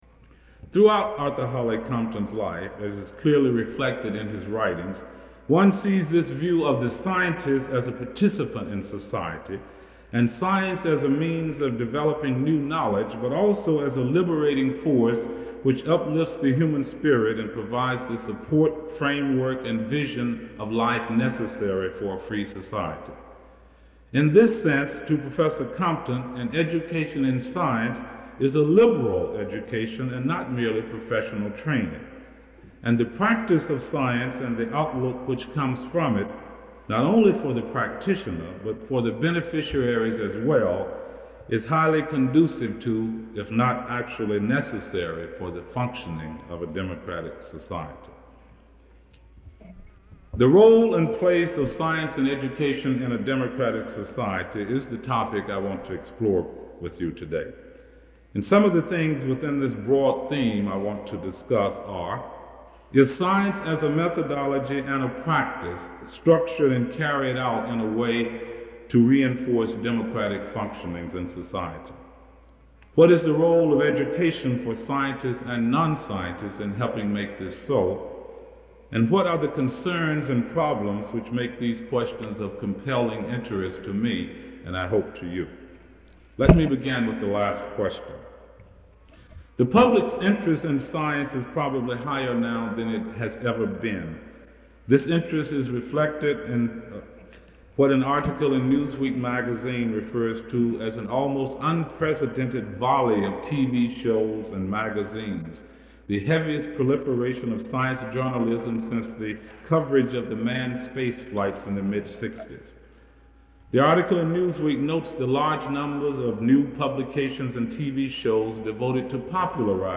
Lecture Title
Arthur Holly Compton Memorial Lecture